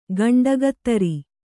♪ gaṇḍagattari